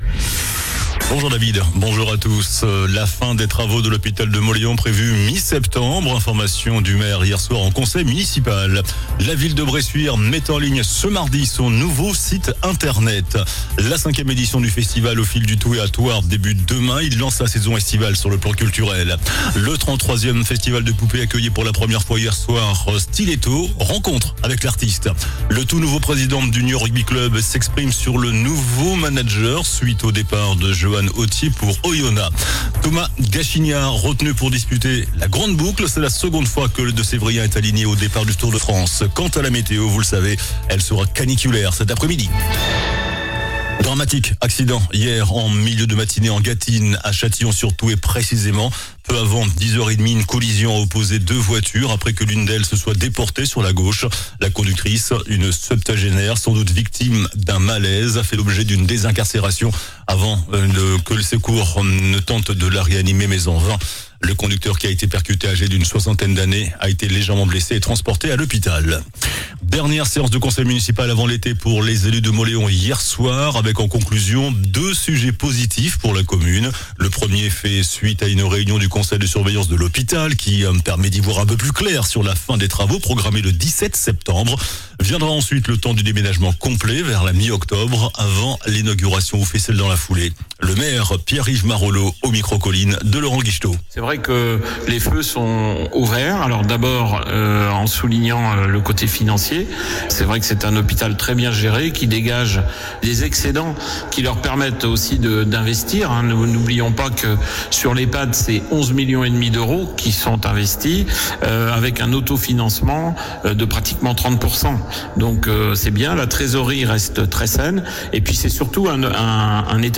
JOURNAL DU 01 JUILLET ( MIDI )